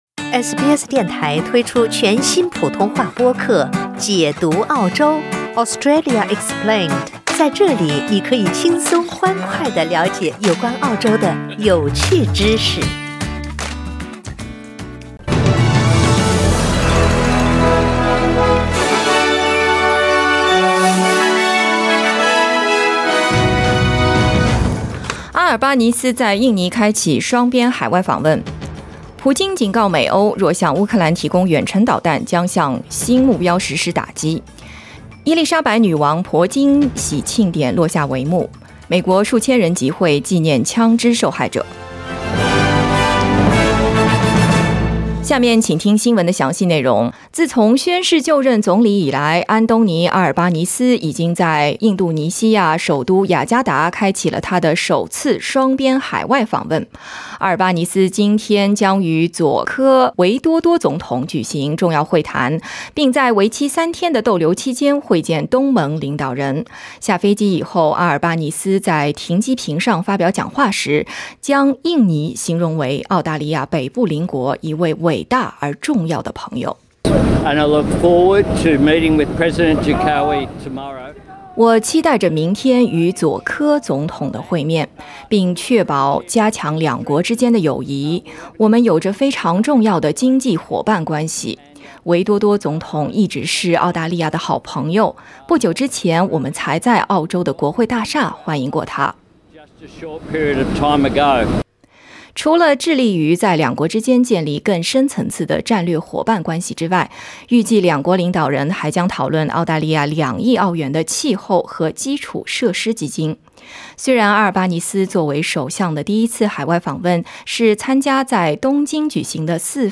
SBS早新闻 (2022年6月6日)
SBS 普通话电台